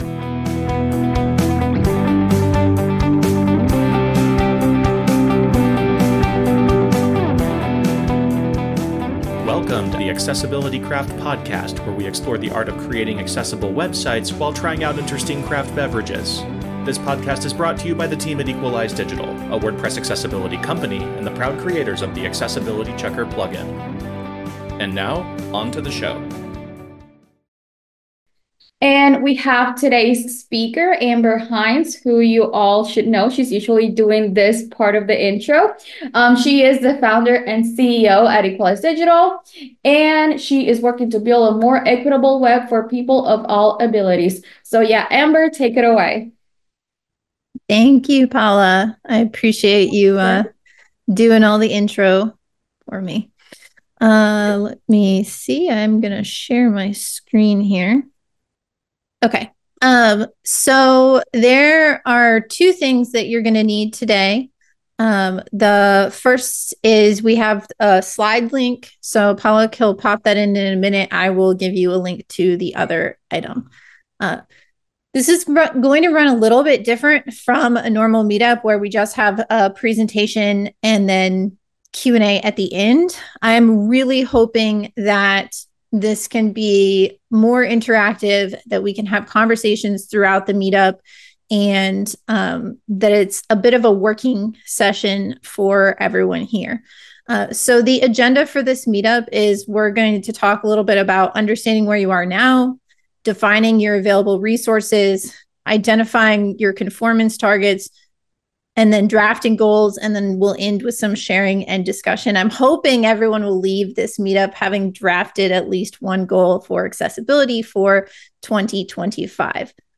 WordPress Accessibility Meetups take place via Zoom webinars twice a month, and anyone can attend.